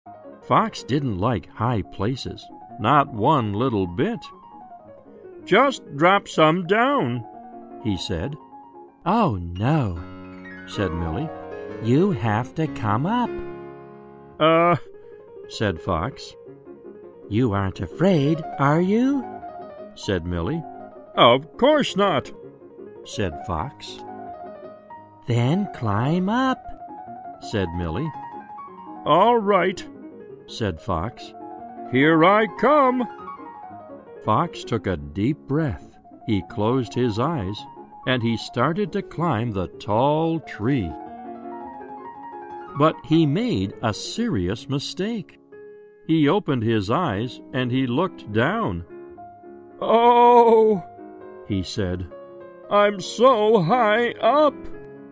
在线英语听力室小狐外传 第47期:高的地方的听力文件下载,《小狐外传》是双语有声读物下面的子栏目，非常适合英语学习爱好者进行细心品读。故事内容讲述了一个小男生在学校、家庭里的各种角色转换以及生活中的趣事。